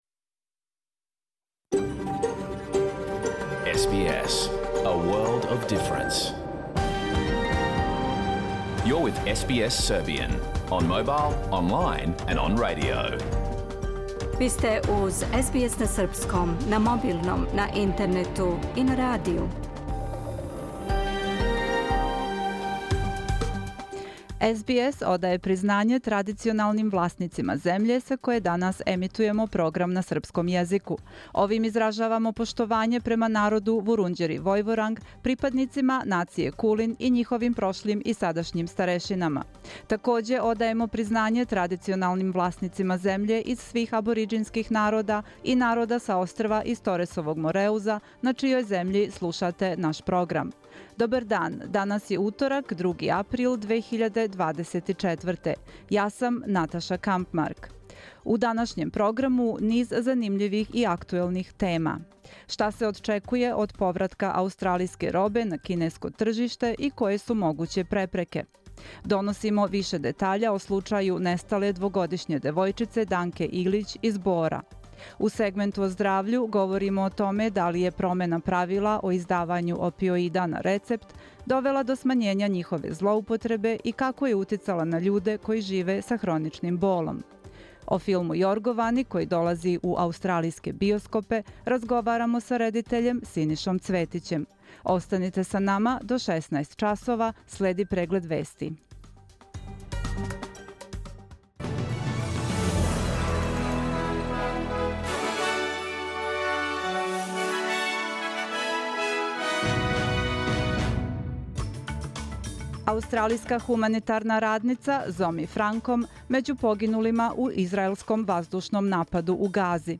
Програм емитован уживо 2. априла 2024. године
Уколико сте пропустили данашњу емисију, можете је послушати у целини као подкаст, без реклама.